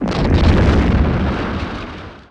Blast01.wav